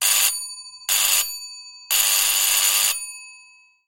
Звуки дверного звонка
Старинный дверной звонок